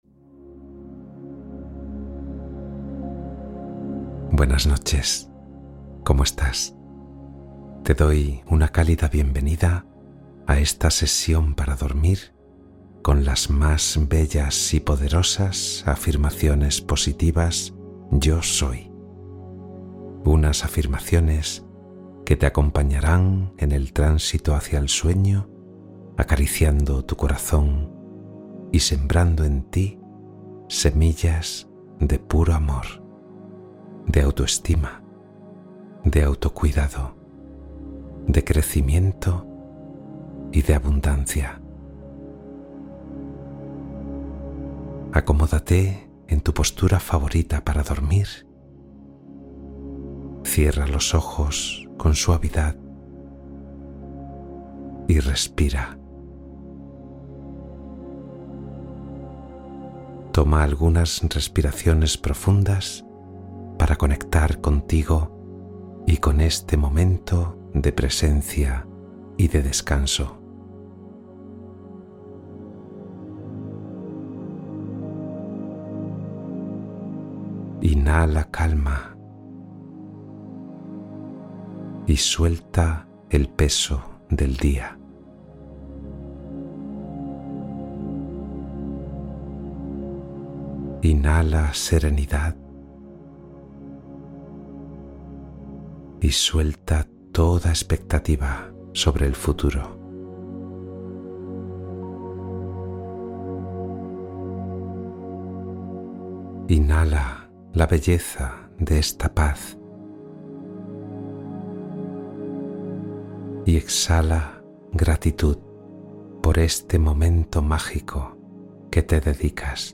Afirmaciones Yo Soy: Meditación Transformadora para Dormir